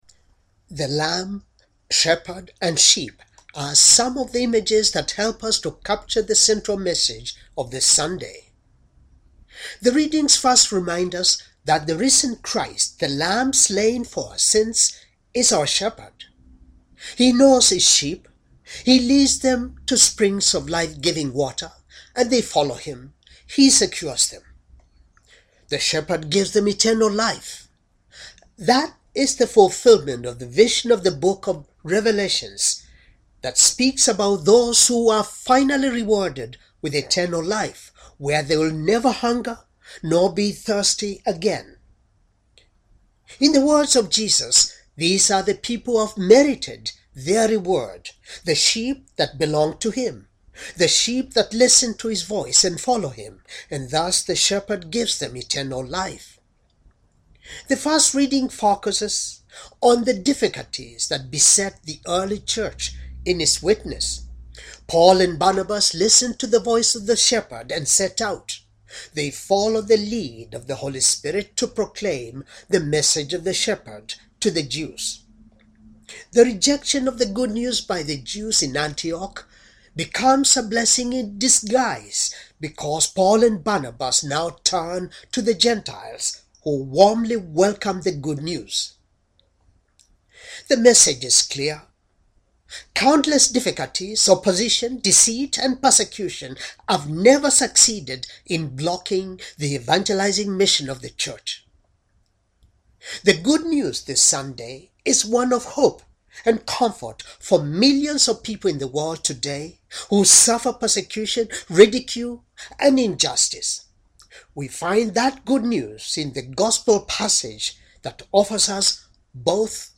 Homily for Fourth Sunday of Easter Year C